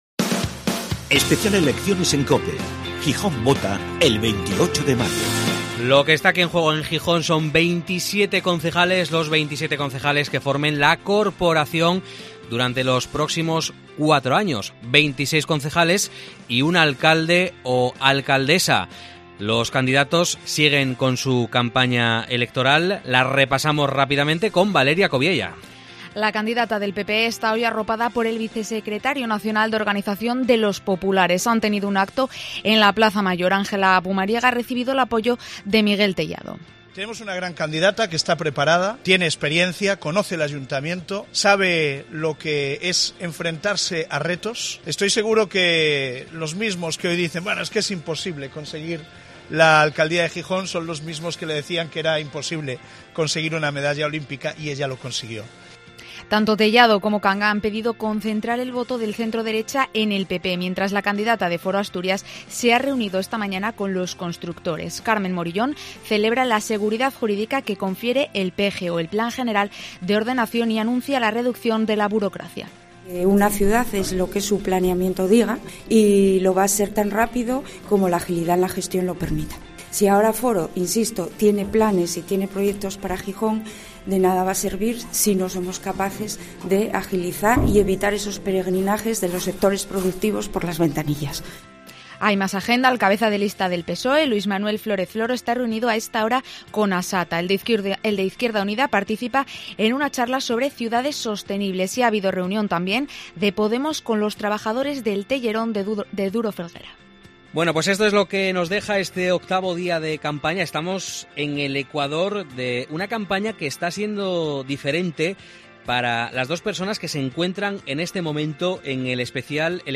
Gijón está en campaña electoral. En COPE hablamos con los candidatos, pero también escuchamos a los ciudadanos, sus inquietudes y peticiones.